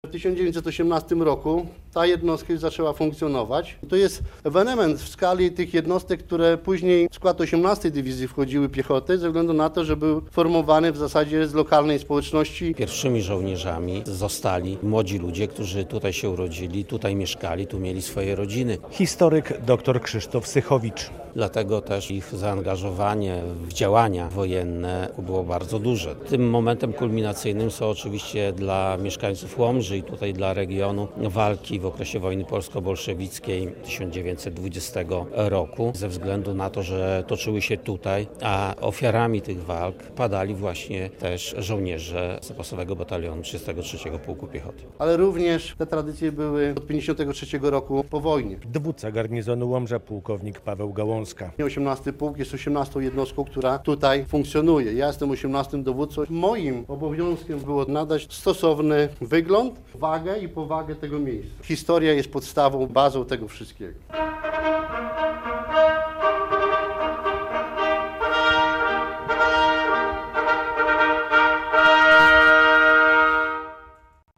Otwarcie Izby Tradycji 18. Łomżyńskiego Pułku Logistycznego - relacja